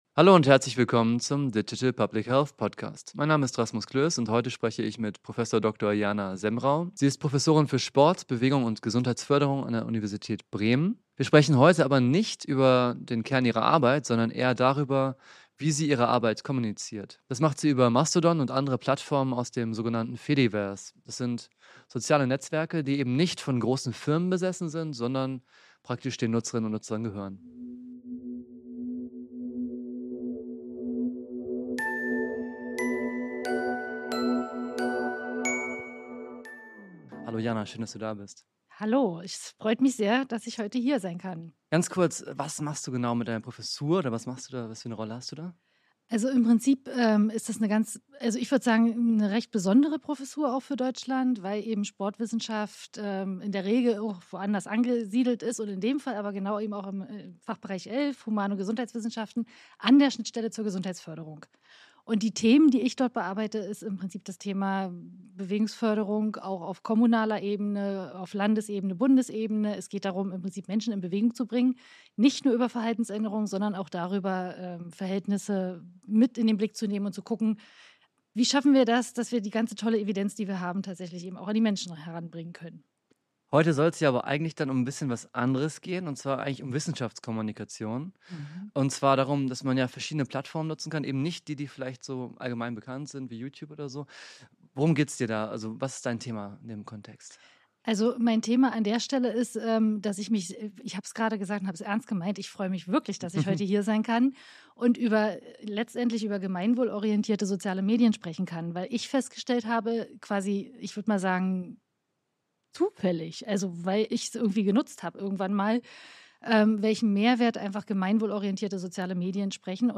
Im Gespräch geht es um Katastrophenschutz, Telemedizin, digitale Dokumentation und die Frage, was davon im Krisenfall wirklich hilft.